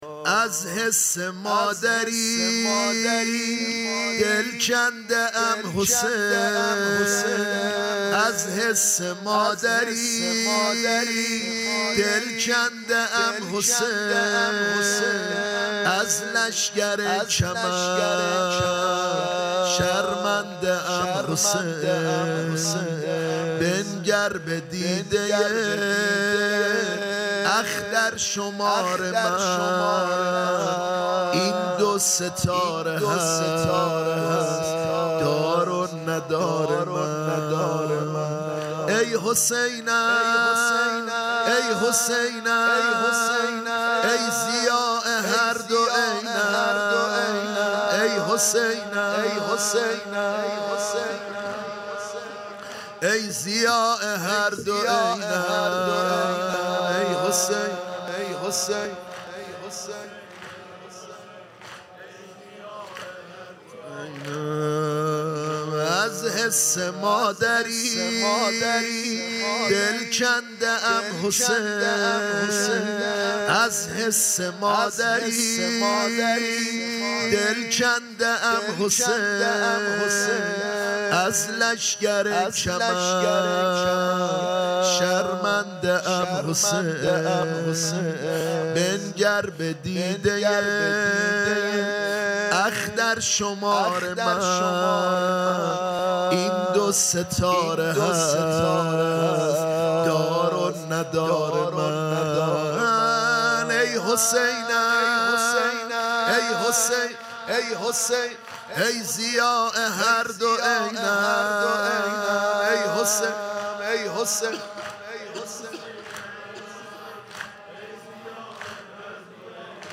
گلزار شهدای گمنام شهرک شهید محلاتی
0 0 مداحی شب چهارم محرم